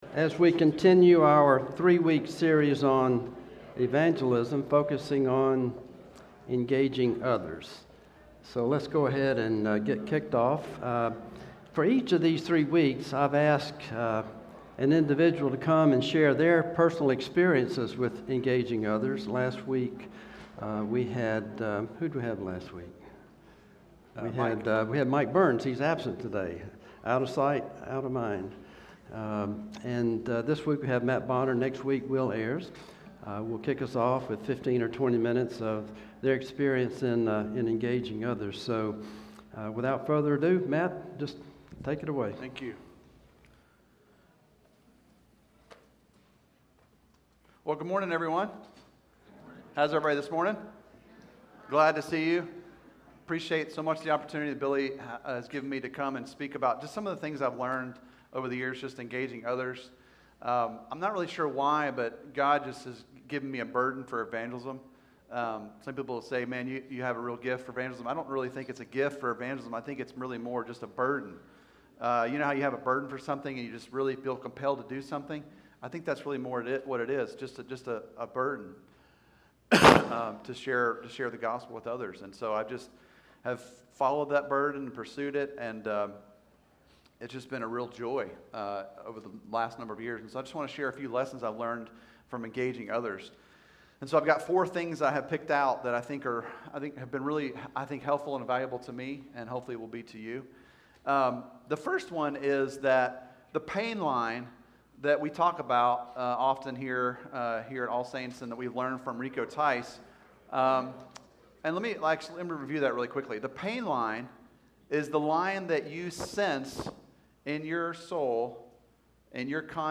Sermons and Lessons from All Saints Presbyterian Church
Sermons and Lessons from All Saints Presbyterian Church (PCA) in Brentwood, TN.